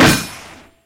combat / Armor